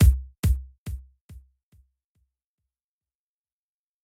Ein 1/4 Delay bei 140 BPM KÖNNTE sich dann so anhören:
kick_delay.mp3